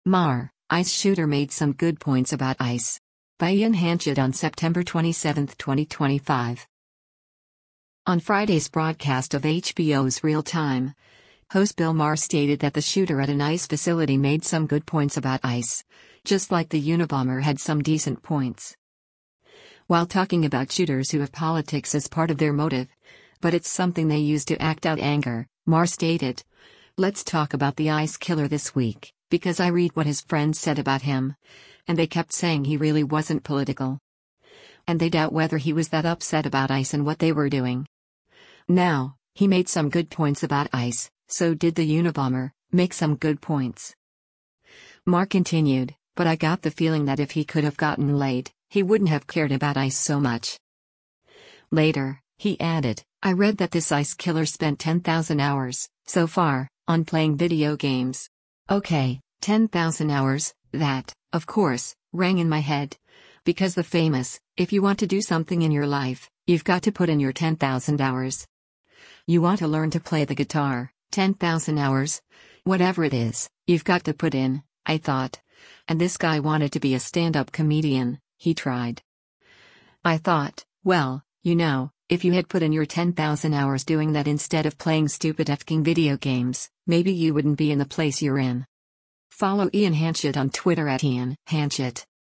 On Friday’s broadcast of HBO’s “Real Time,” host Bill Maher stated that the shooter at an ICE facility “made some good points about ICE,” just like the Unabomber had some decent points.